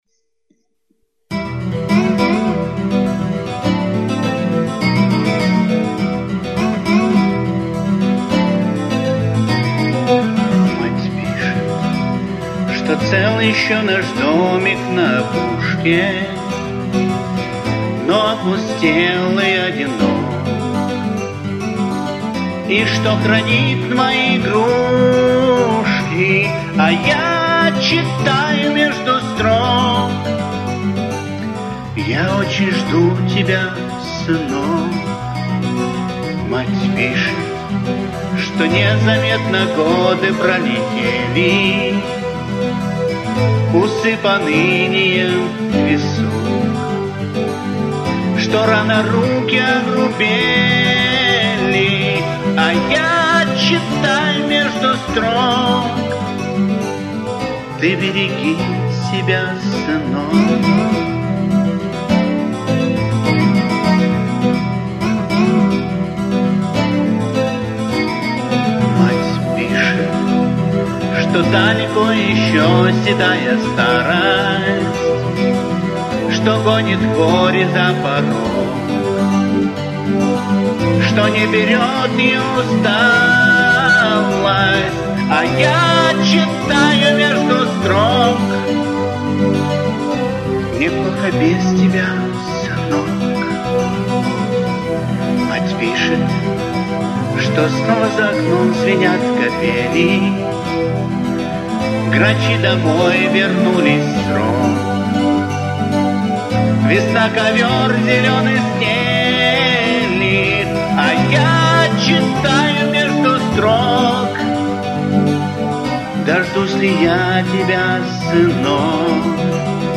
Сори за качество)))
Пою на простой ноутбук))) со встроенным микрофоном)))